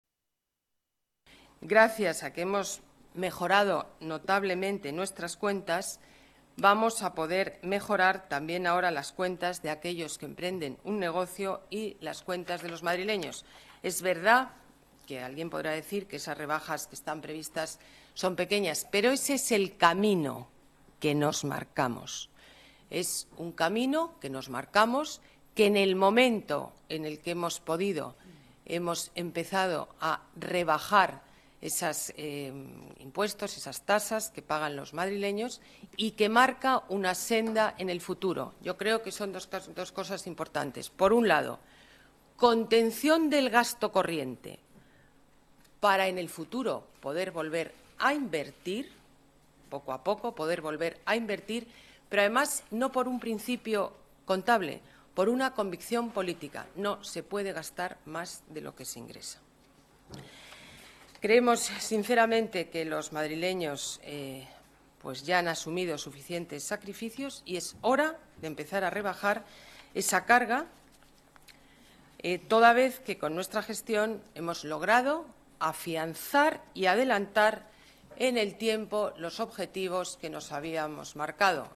Declaraciones alcaldesa de Madrid, Ana Botella: Plan de Ajuste, bajada progresiva de impuestos Declaraciones alcaldesa de Madrid, Ana Botella: Plan de Ajuste, mejora económica repercutirá en los madrileños Vídeo rueda de prensa Junta de Gobierno.